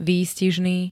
Zvukové nahrávky niektorých slov
3hok-vystizny.ogg